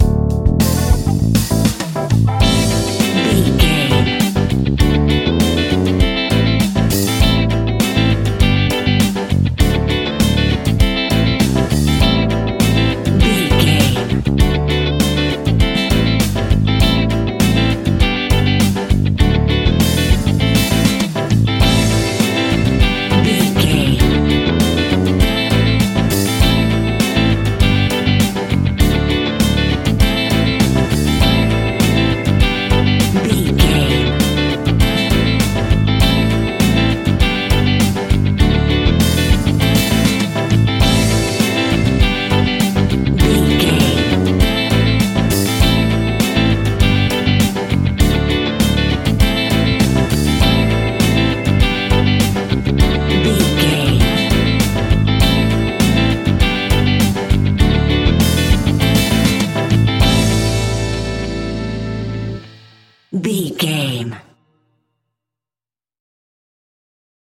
Uplifting
Aeolian/Minor
pop rock
fun
energetic
acoustic guitars
drums
bass guitar
electric guitar
piano
organ